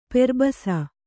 ♪ perbasa